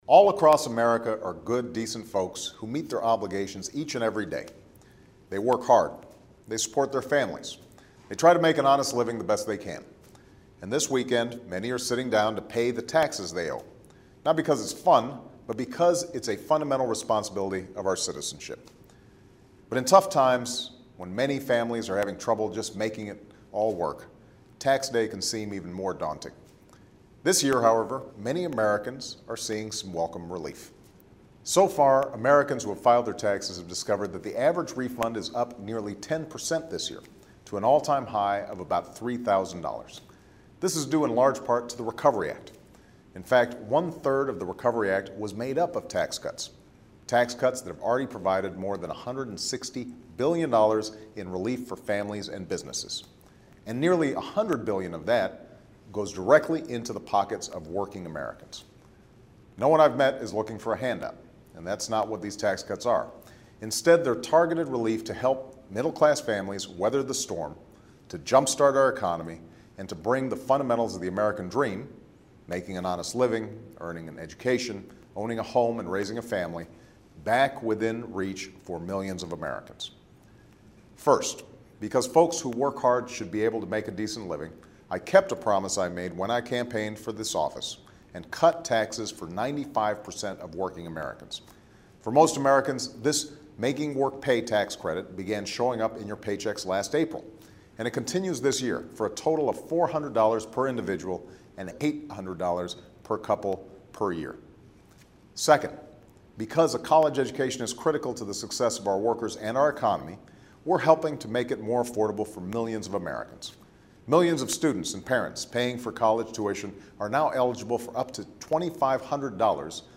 Remarks of President Barack Obama